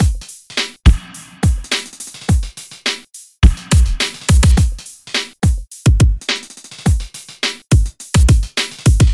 旧学校 鼓循环6
描述：嘻哈风格，请欣赏！！。
Tag: 105 bpm Hip Hop Loops Drum Loops 1.54 MB wav Key : Unknown